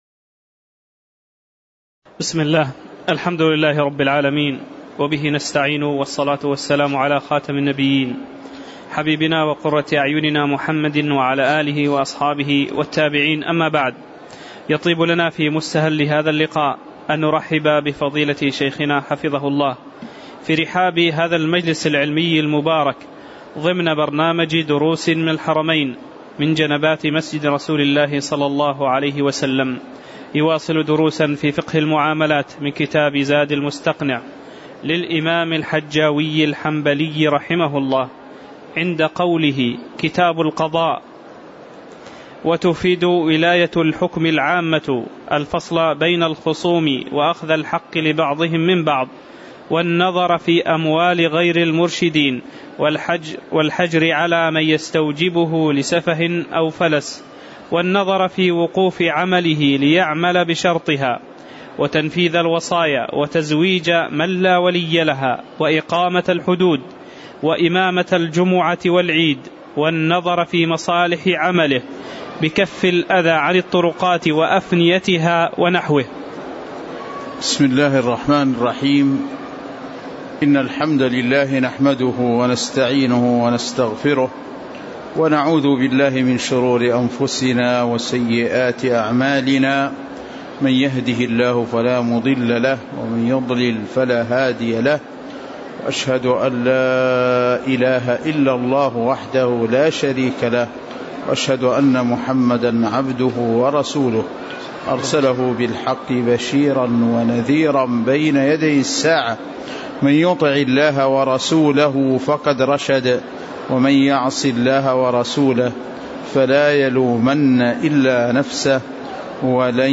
تاريخ النشر ٢٩ جمادى الآخرة ١٤٣٨ هـ المكان: المسجد النبوي الشيخ